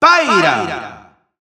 Announcer pronouncing Pyra's name in Spanish.
Pyra_Spanish_Announcer_SSBU.wav